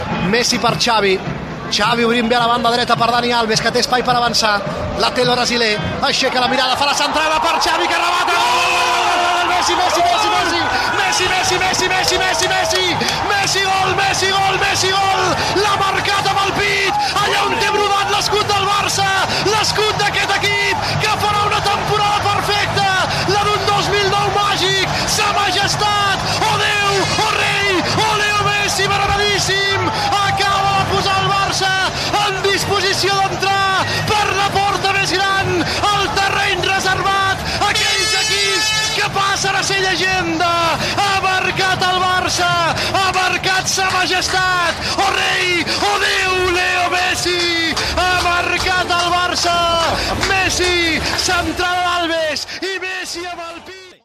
Narració del gol amb el pit de Leo Messi al partit Futbol Club Barcelona contra Estudiantes de la Plata en la final del Mundial de Clubs, celebrada a l'Estadi Xeic Zayed, a Abu Dhabi
Esportiu